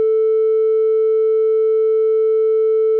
sin.wav